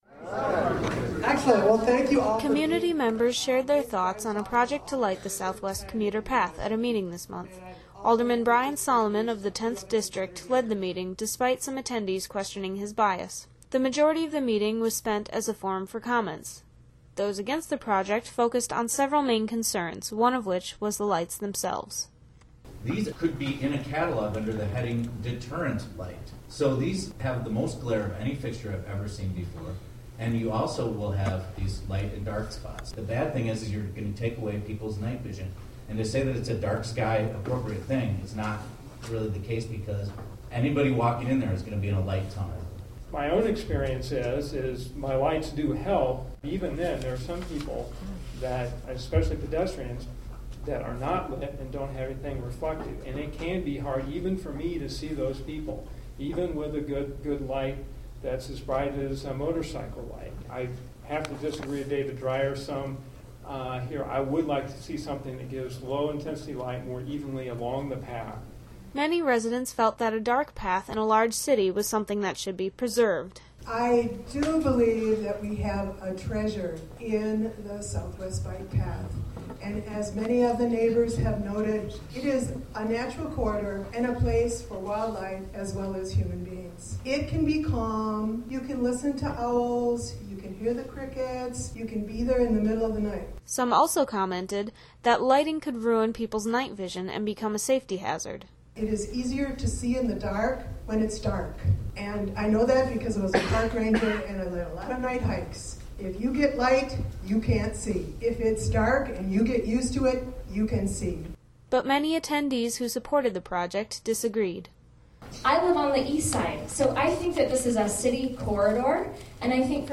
Armed with raised voices and strong opinions, few commentators took a neutral stance. The majority argued against lighting the path, but many – especially bicyclists – advocated for lighting.